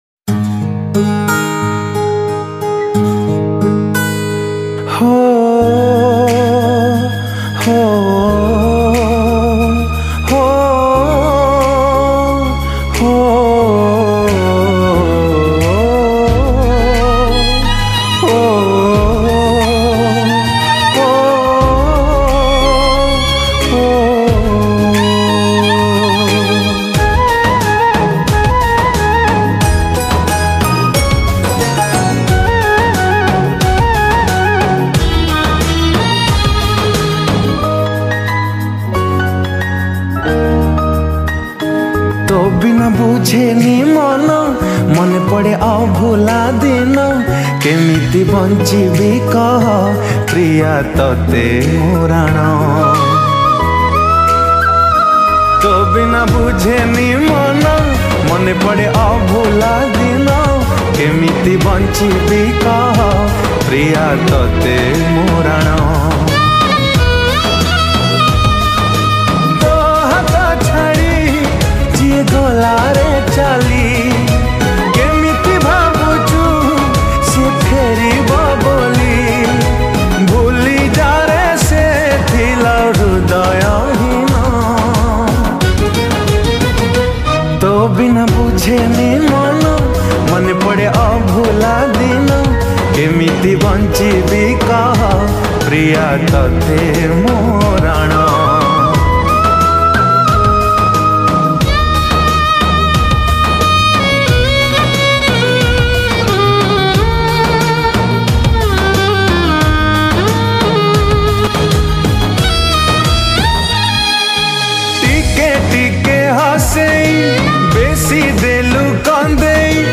Sad Romantic Songs